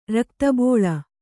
♪ rakta bōḷa